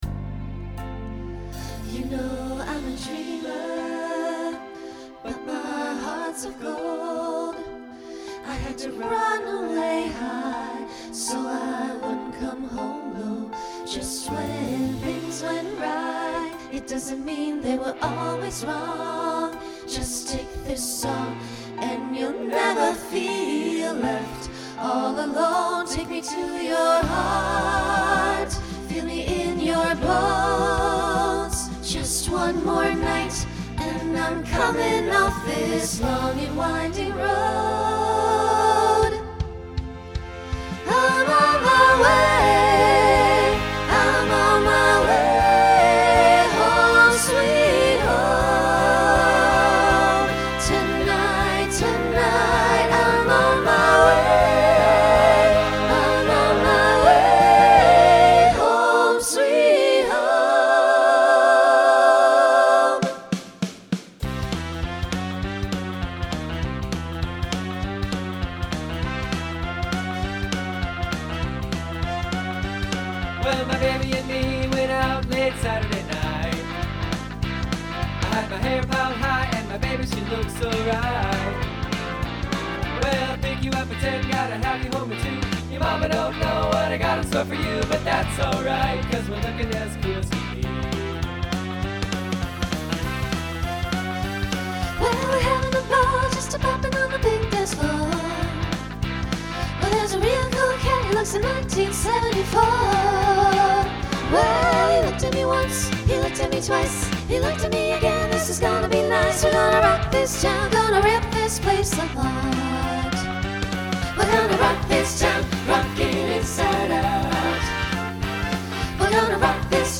Genre Rock , Swing/Jazz
Voicing SATB